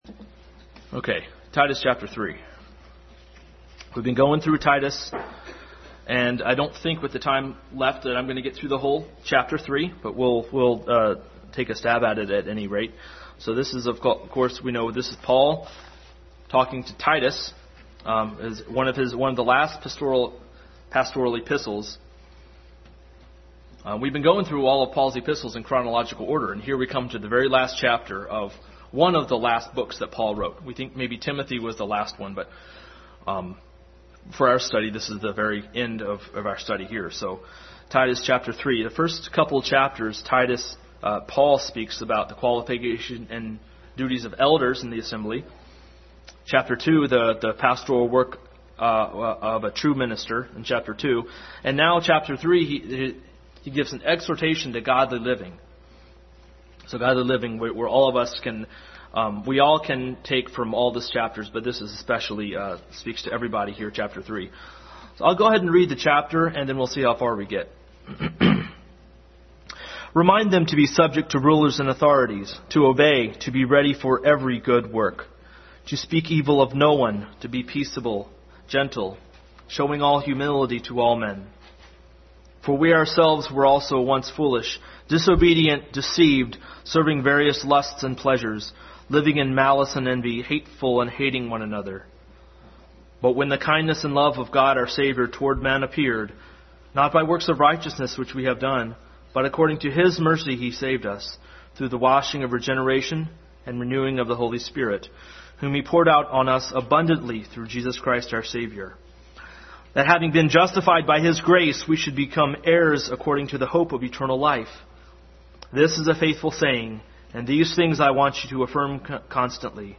Titus 3:1-8 Passage: Titus 3:1-8, Ephesians 2:4 Service Type: Sunday School